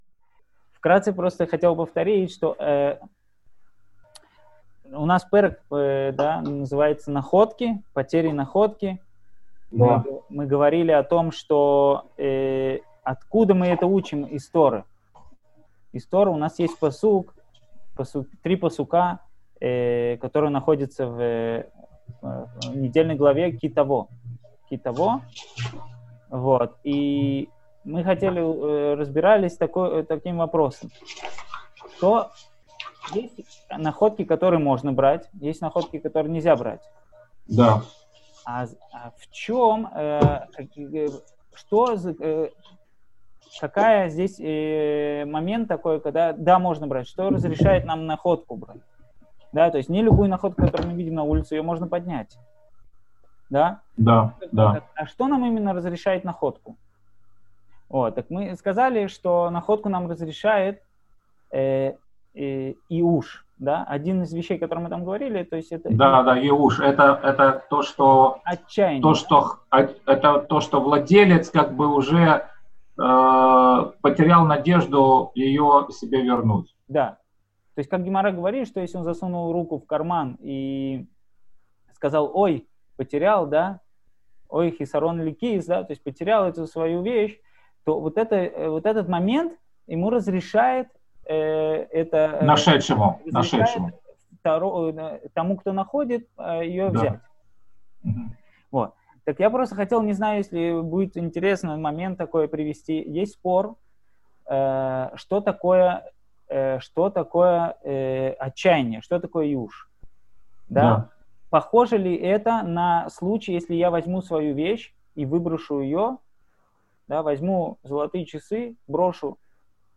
Цикл уроков по трактату Бава Мециа